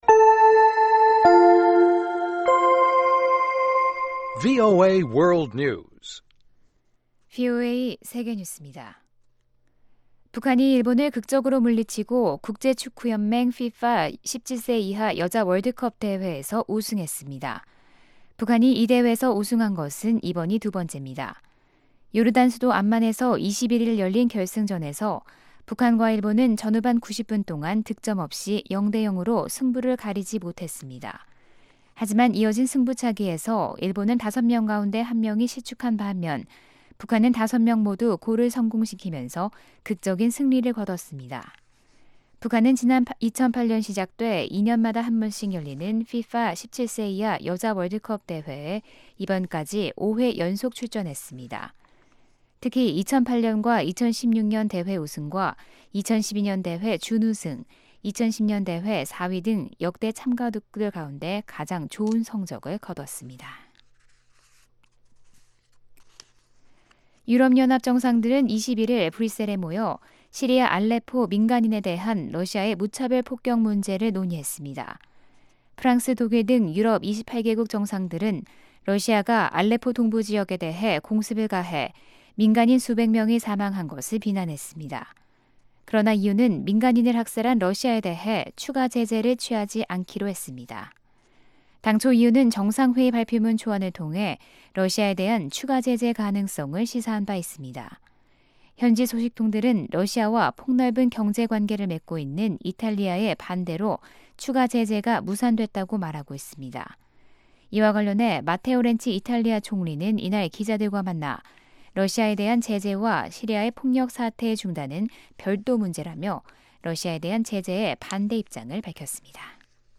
VOA 한국어 방송의 아침 뉴스 프로그램 입니다. 한반도 시간 매일 오전 5:00 부터 6:00 까지, 평양시 오전 4:30 부터 5:30 까지 방송됩니다.